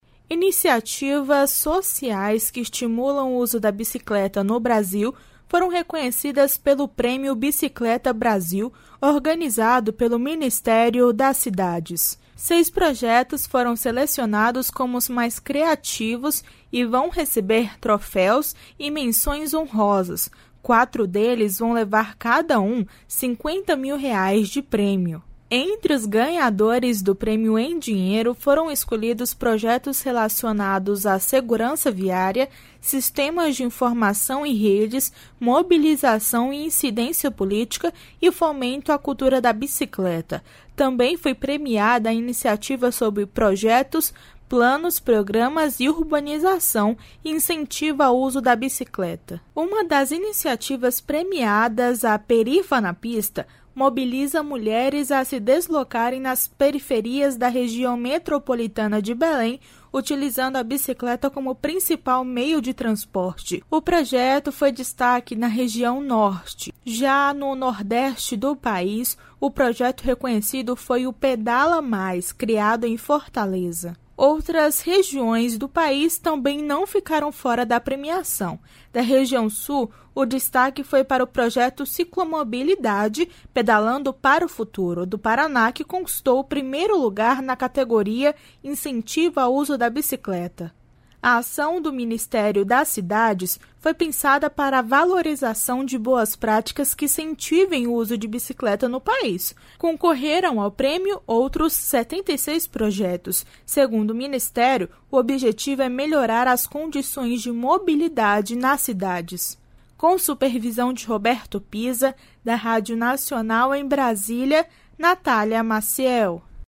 Para isso, basta aproximar o celular da maquininha de pagamento do vendedor, como explicou o diretor de Organização do Sistema Financeiro do BC, Renato Gomes.